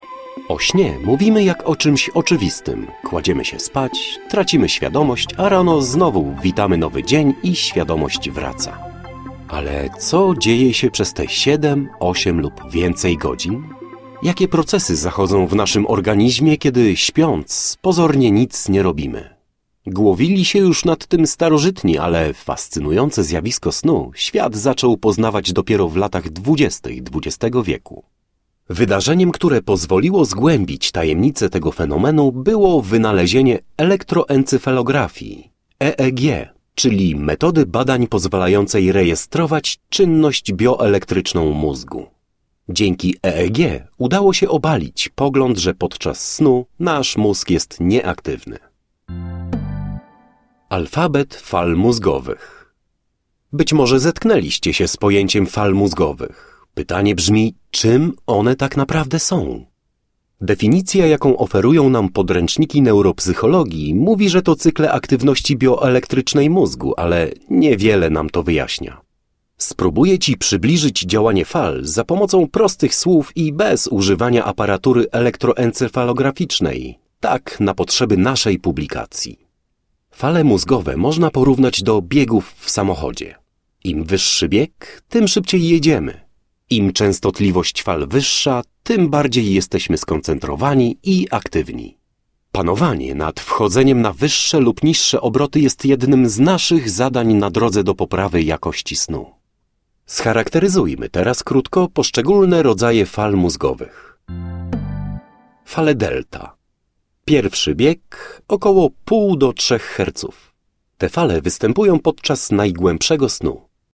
Sztuka spania i wstawania - Audiobook mp3